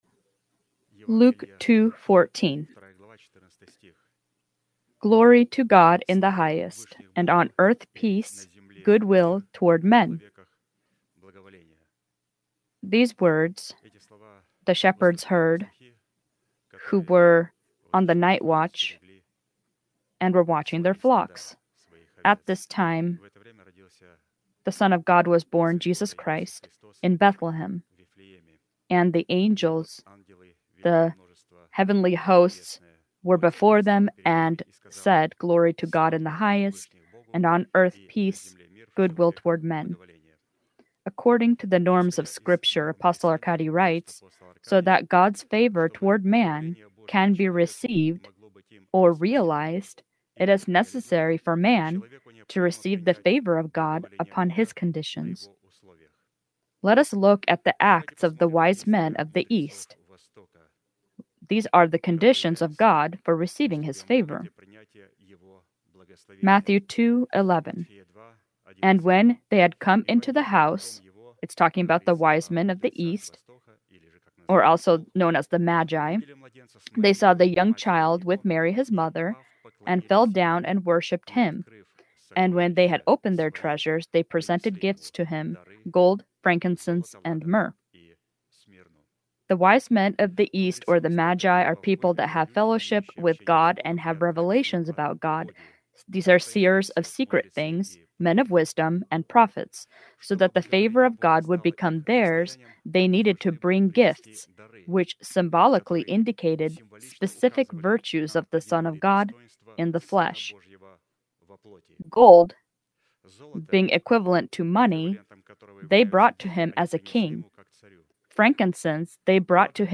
Sermon title: